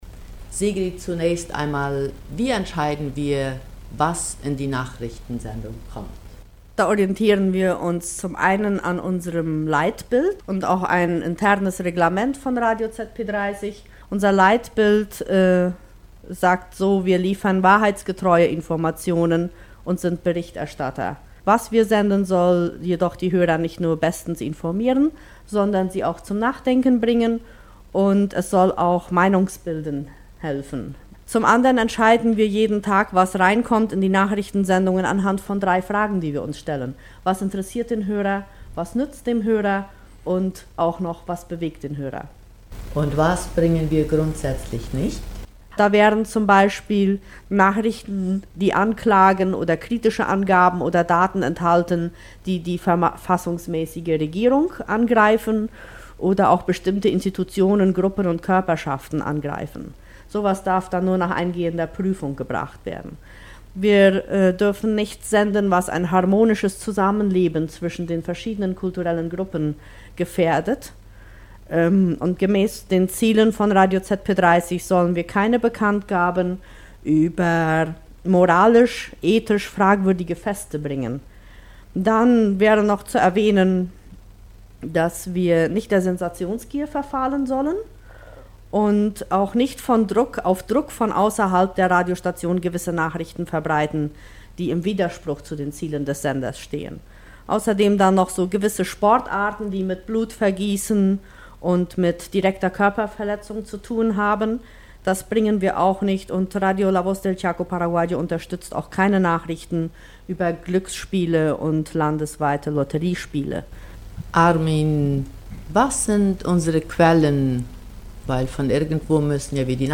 Gespräch Deutsche Presse Abteilung - Nachrichten - HB RB SE AE